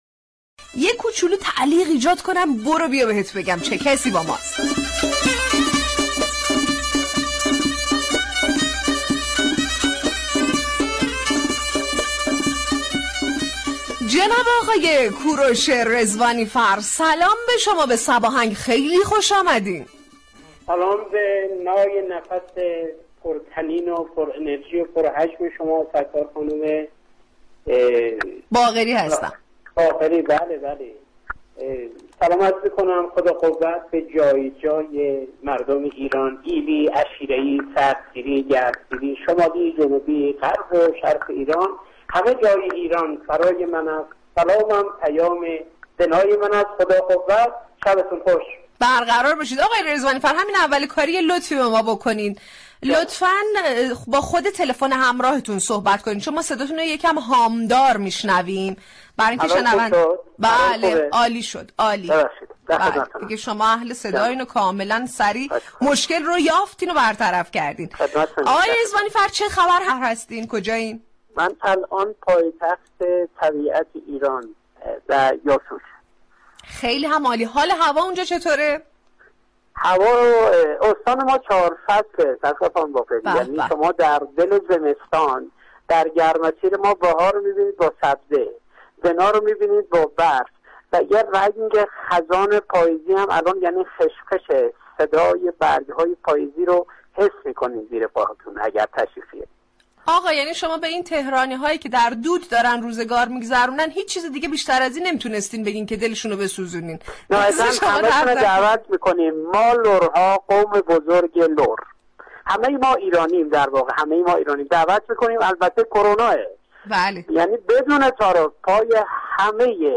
خواننده محلی در برنامه صباهنگ مهمان رادیو صبا شد
در گفتگو با برنامه صباهنگ